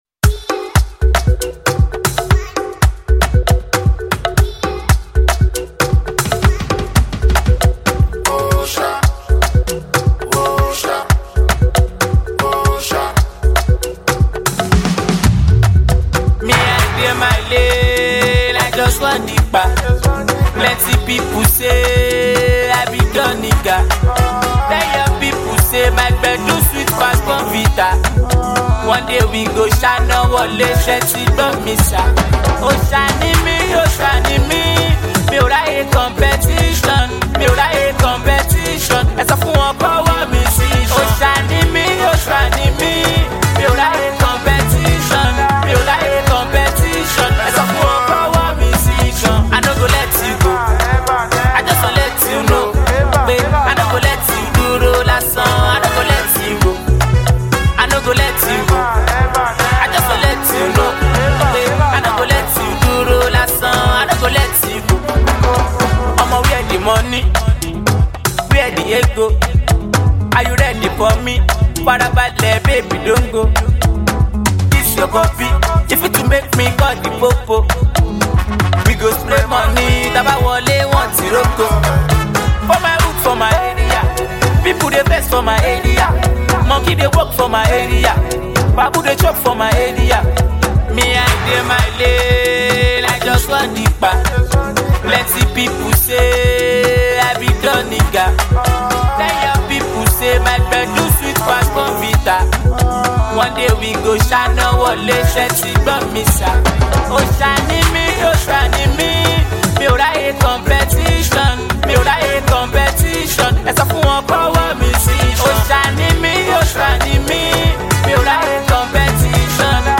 Talented rapper and also singer